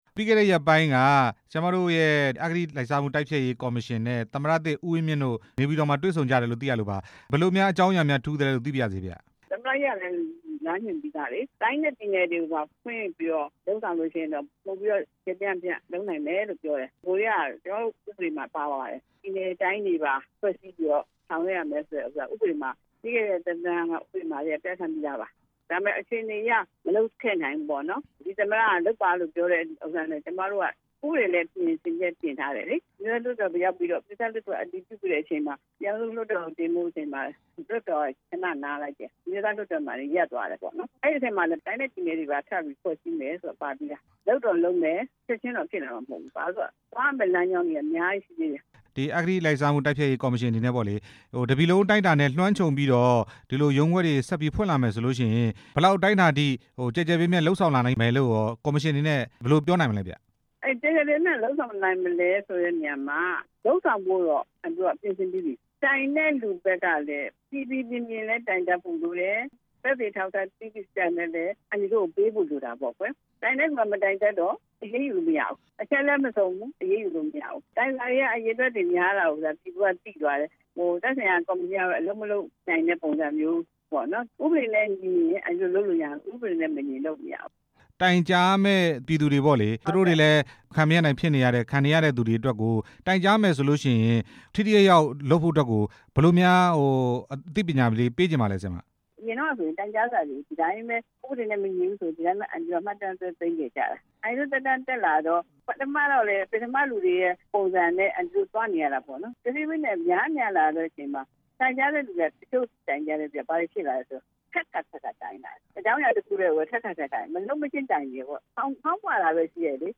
အဂတိလိုက်စားမှု တိုက်ဖျက်ရေး ရုံးခွဲတွေဖွင့်မယ့်အကြောင်း မေးမြန်းချက်